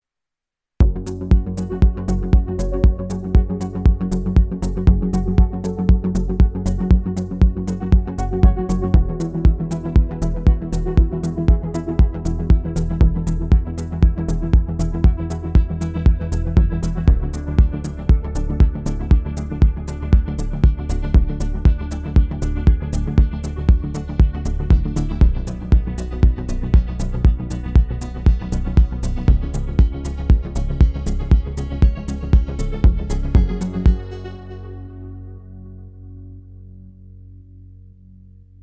Помогите накрутить Slap house bass